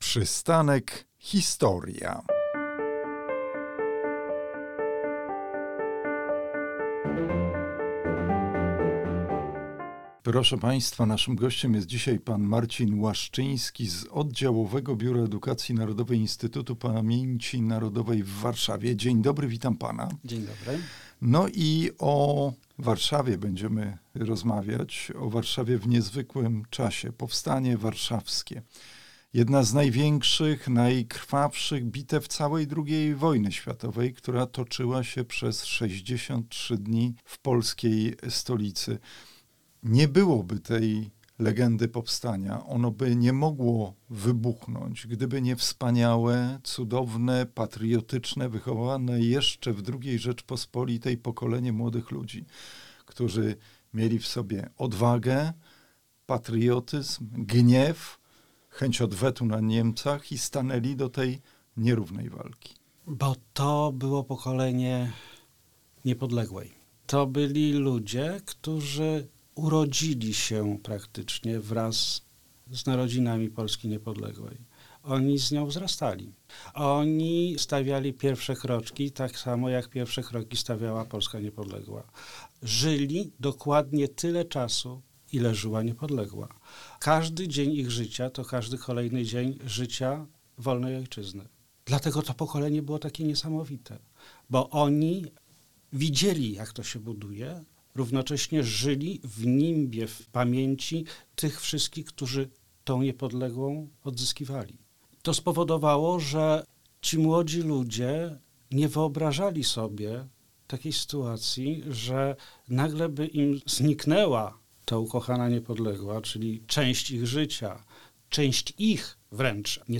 Przystanek Historia – rozmowy Przystanek Historia